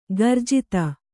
♪ garjita